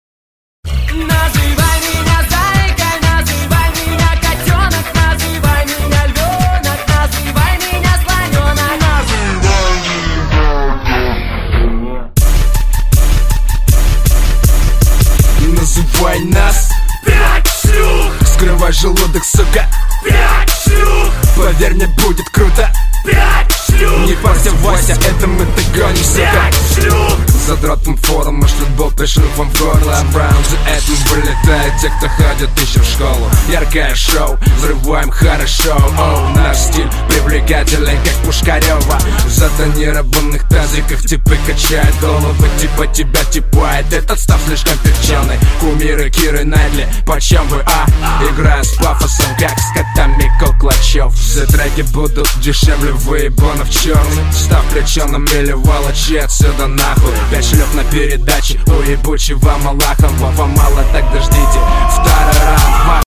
• Качество: 128, Stereo
Хип-хоп
русский рэп
Bass
злые
агрессивные